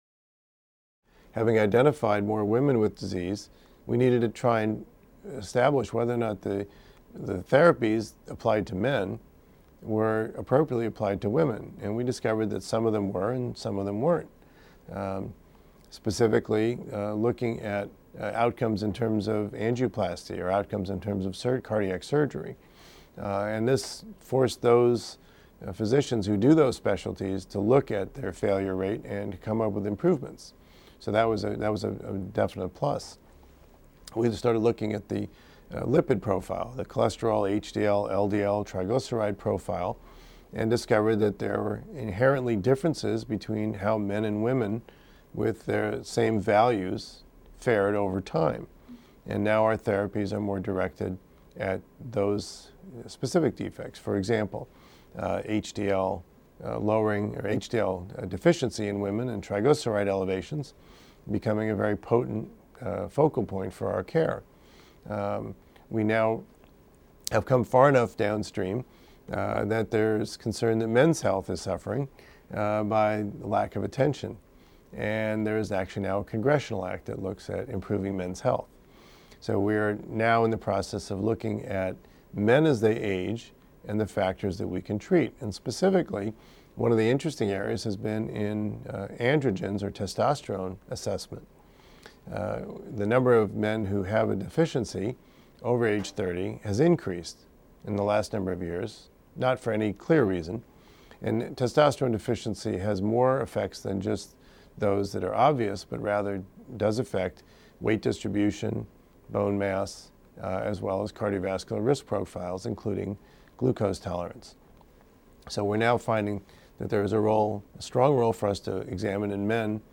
This podcast is an audio-only version of the videotaped segments of the Introduction to Cardiac Care online seminar.